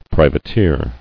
[pri·va·teer]